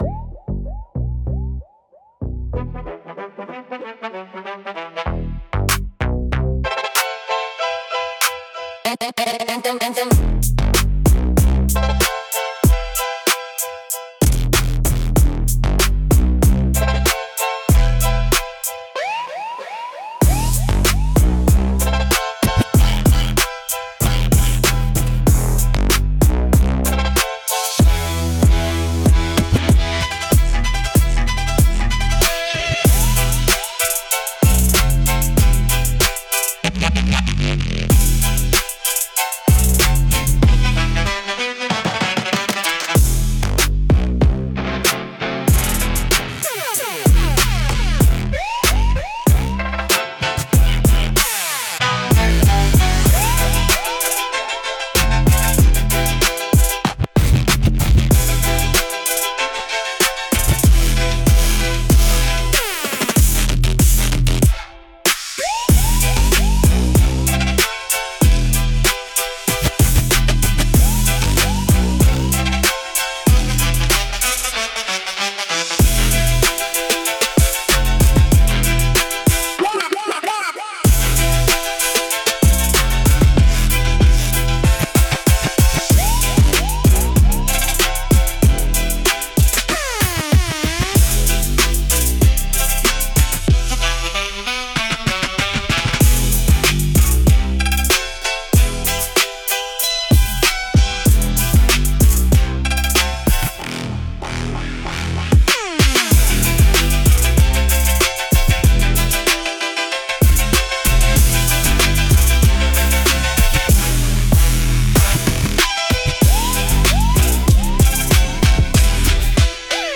Instrumental - Pressure Cooker